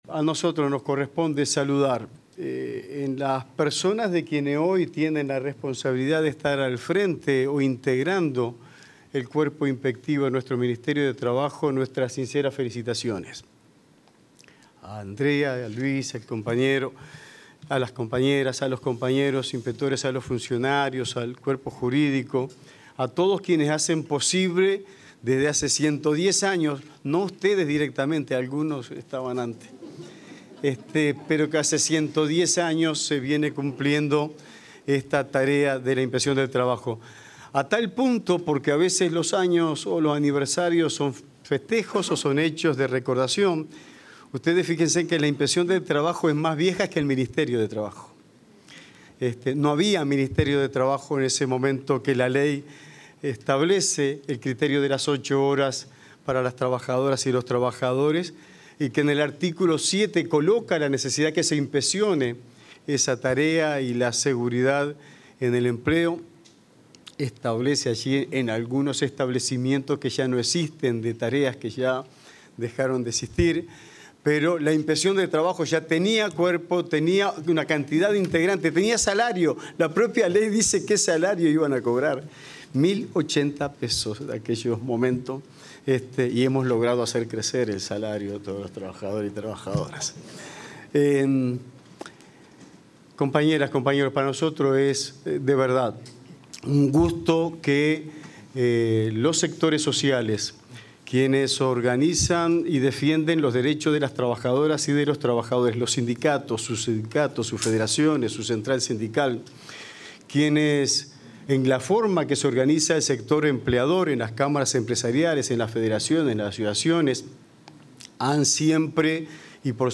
Palabras de autoridades en el 110.º aniversario de la IGTSS
Palabras de autoridades en el 110.º aniversario de la IGTSS 18/11/2025 Compartir Facebook X Copiar enlace WhatsApp LinkedIn En la conmemoración del 110.º aniversario de la Inspección General del Trabajo y de la Seguridad Social (IGTSS), se expresaron el ministro de Trabajo y Seguridad Social, Juan Castillo, y el inspector general del Trabajo y la Seguridad Social, Luis Puig.